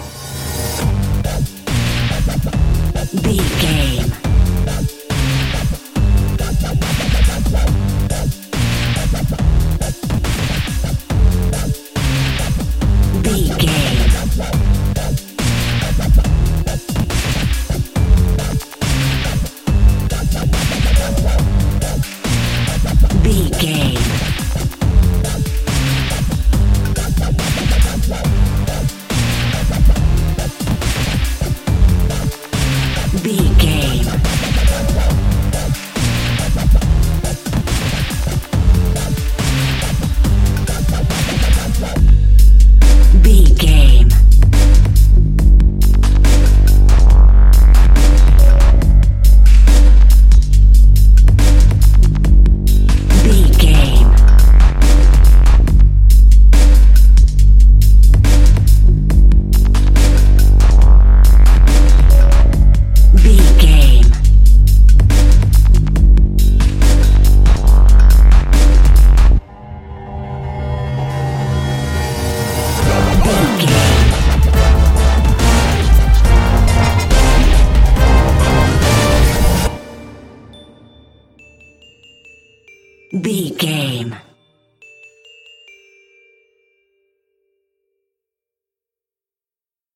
Aeolian/Minor
drum machine
synthesiser
orchestral
orchestral hybrid
dubstep
aggressive
energetic
intense
strings
drums
bass
synth effects
wobbles
epic